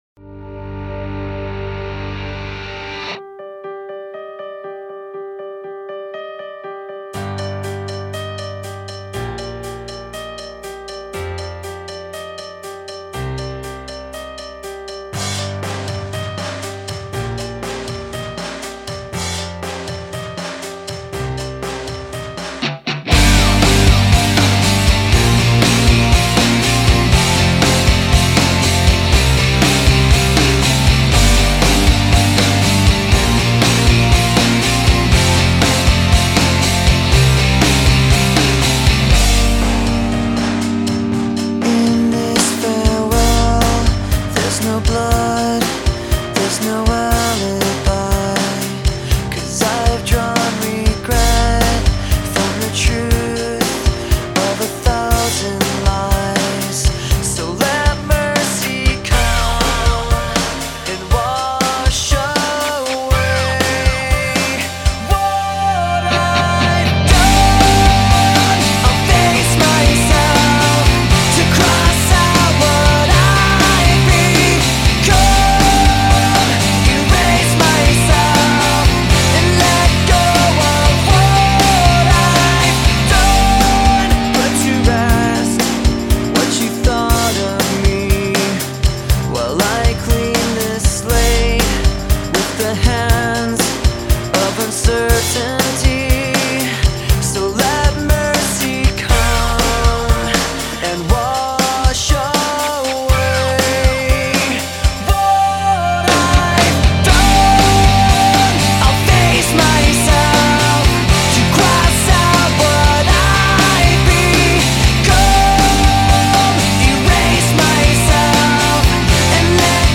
هارد راک